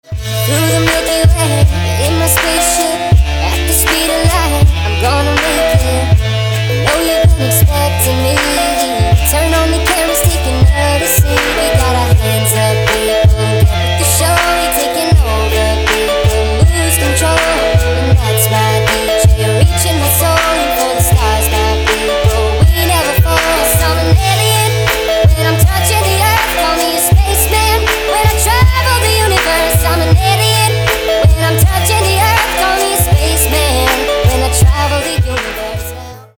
красивые
женский вокал
chilltrap